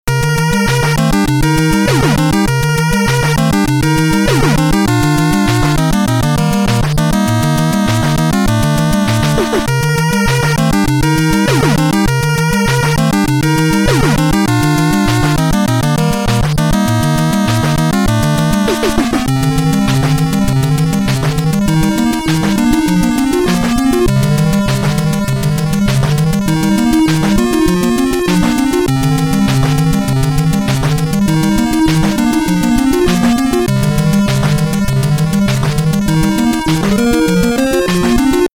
The background music